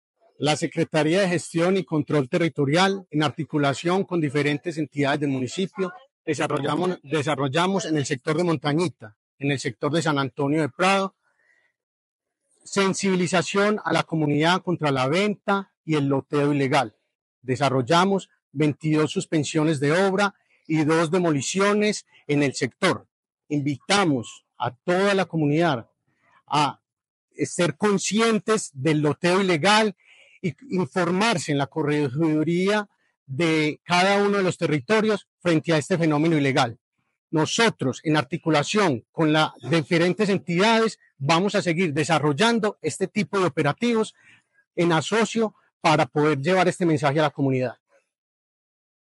Palabras de Carlos Trujillo, subsecretario de Control Urbanístico de Medellín